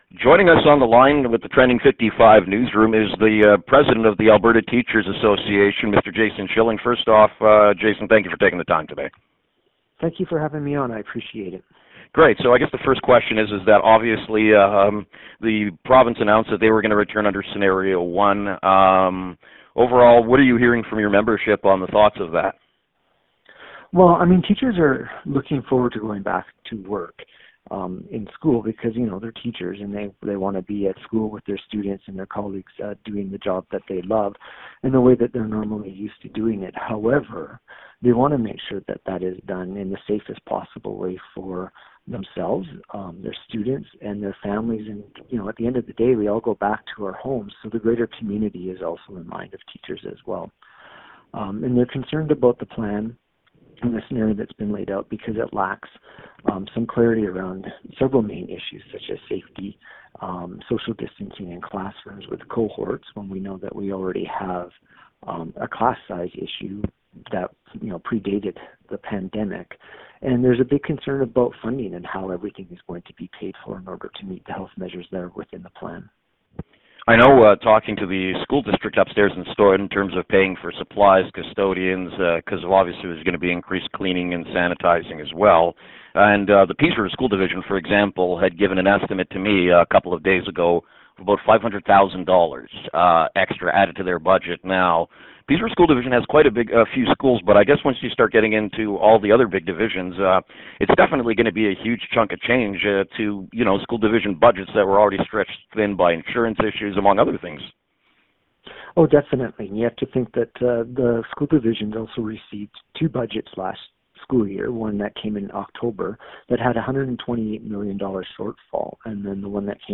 The full interview is below: